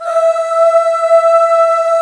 F.CHORUS E4.wav